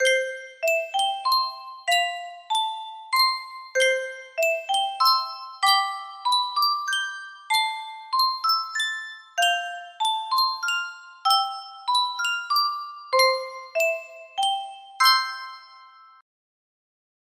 Yunsheng Music Box - Brahms Tragic Overture 6002 music box melody
Full range 60